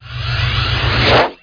magic.mp3